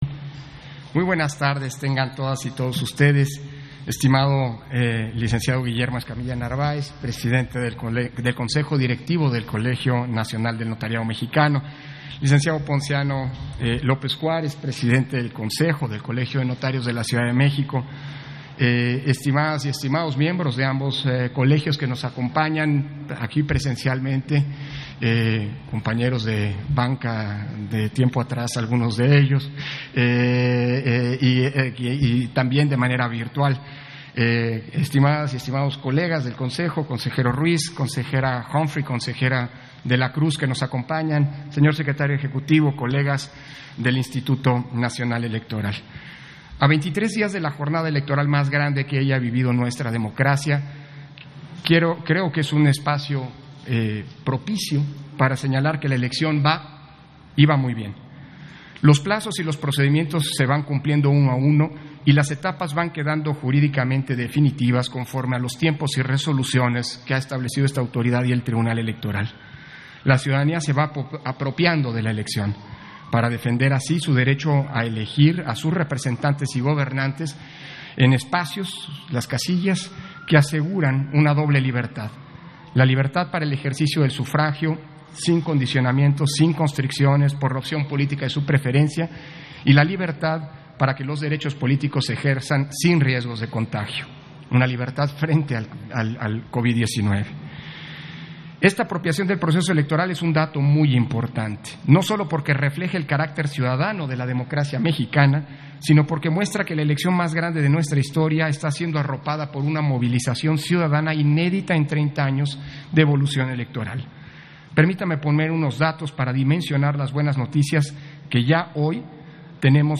Intervención de Lorenzo Córdova, durante la firma de convenio de colaboración INE – Colegio Nacional del Notariado – Colegio de Notarios de la Ciudad de México